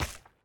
resin_place3.ogg